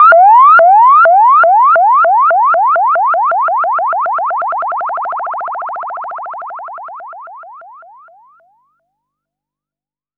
Bouncing Ball.wav